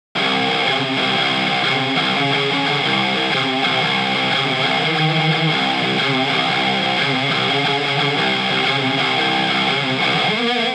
サウンドデモ
エレキギター（原音）
SA-3_ElectricGuitar_Bypassed.wav